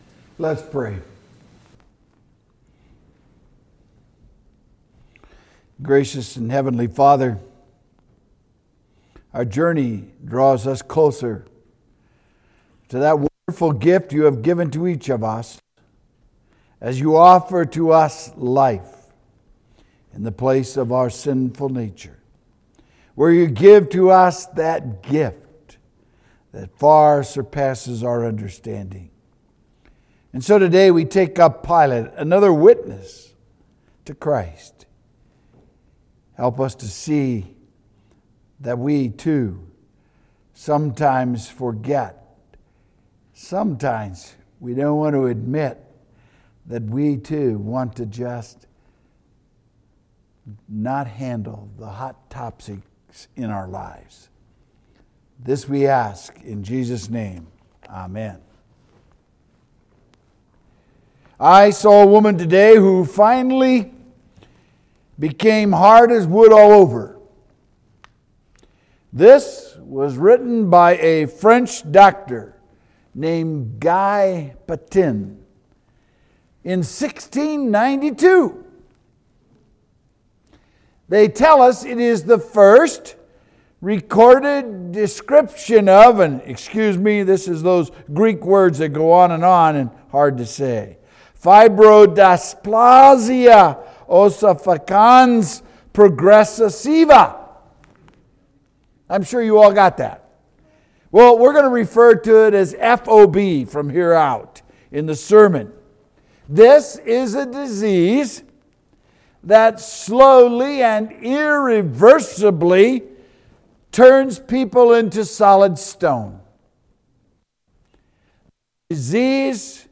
Witnesses to Christ: Pontius Pilate (Sermon audio)